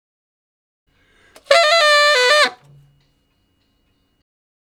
066 Ten Sax Straight (D) 36.wav